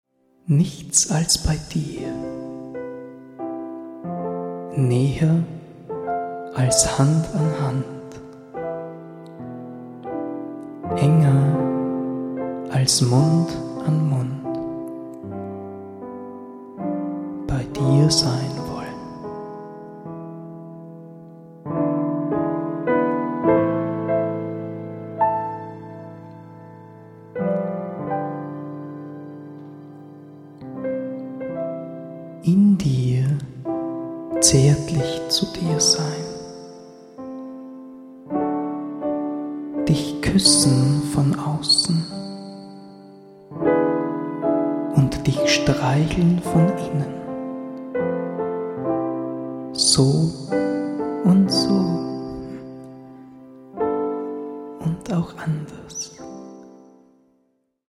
Eine musikalische Dichterlesung